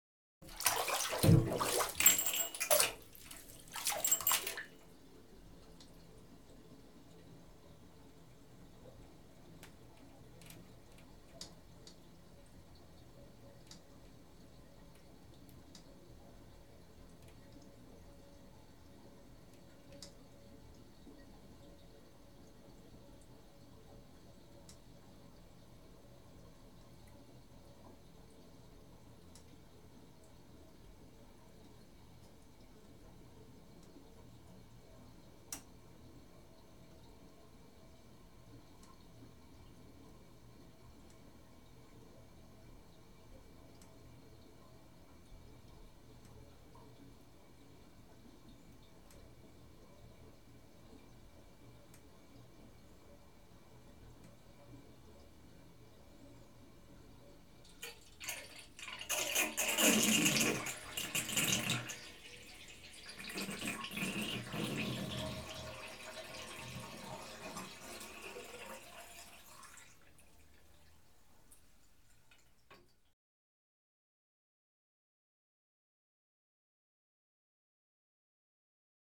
Звук человека выходящего из ванной, вытаскивающего пробку слива, долгого стекания воды с бульканьем и воронкой